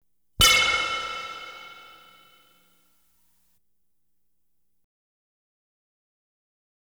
Light Beam Hit Sound Effect
Download a high-quality light beam hit sound effect.
light-beam-hit-8.wav